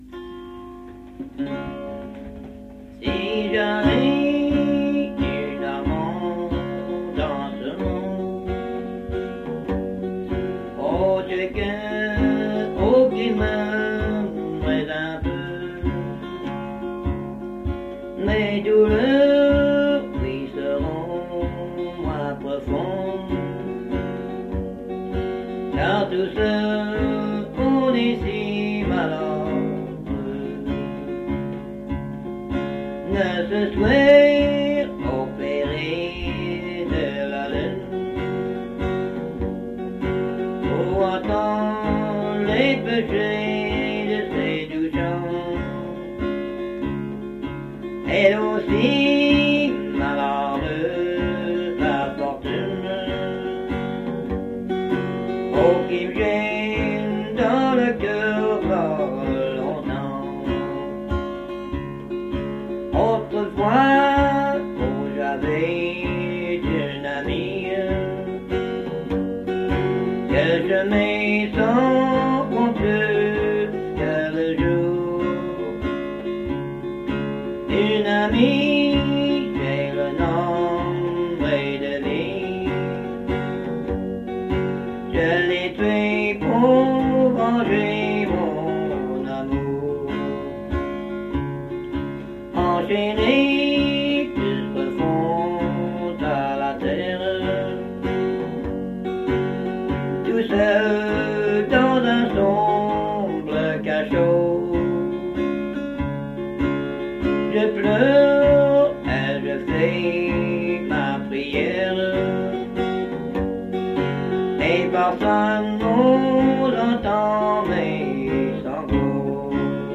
Emplacement Cap St-Georges
Avec guitare